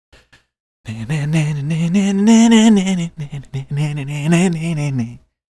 HUMMING SONG
HUMMING-SONG.mp3